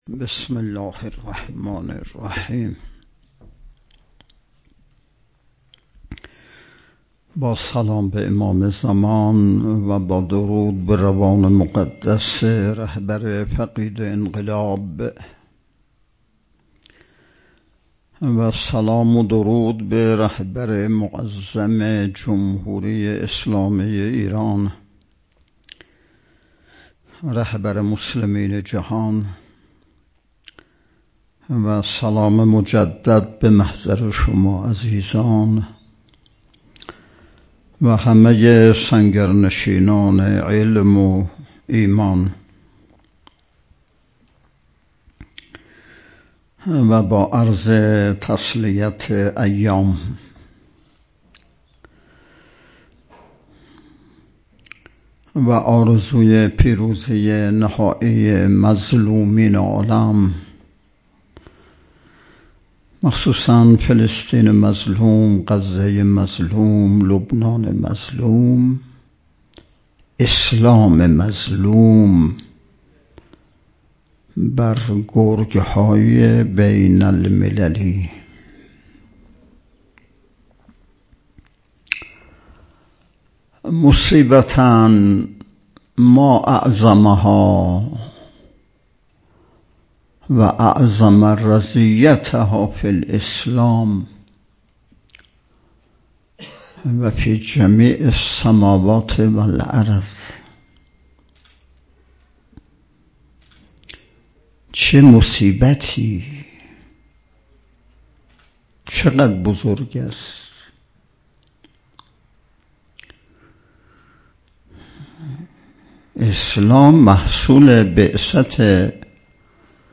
بیستمین نشست ارکان شبکه تربیتی صالحین بسیج با موضوع تربیت جوان مؤمن انقلابی پای کار، صبح امروز ( ۲ مرداد) با حضور و سخنرانی نماینده ولی فقیه در استان، برگزار شد.